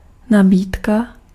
Ääntäminen
France: IPA: [yn pʁo.po.zi.sjɔ̃]